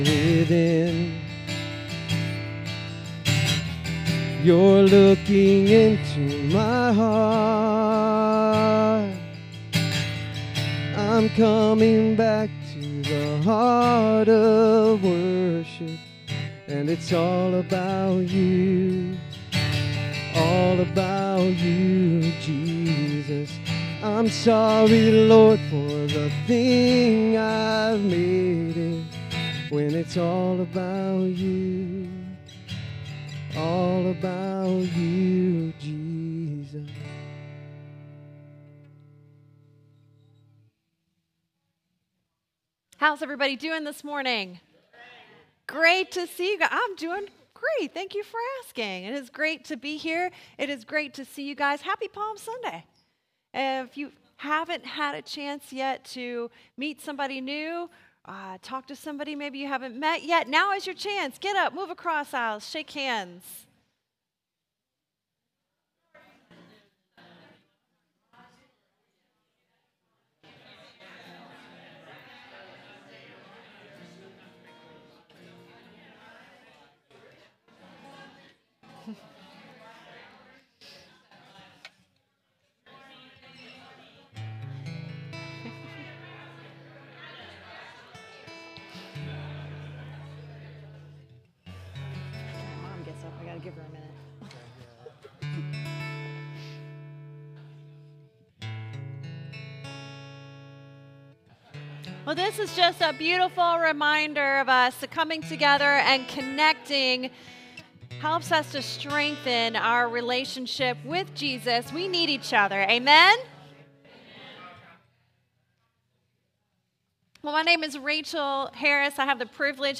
Download Download Reference Acts 4:13, 19:11-29a; Philippians 2:12-13 Sermon Notes Click Here for Notes 250413.pdf SERMON DESCRIPTION I was driving to work and a person cut me off.